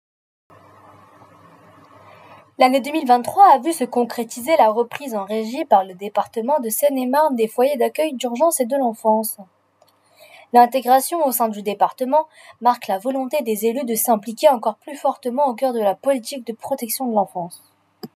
extrait d'un casting voix off
15 - 25 ans - Mezzo-soprano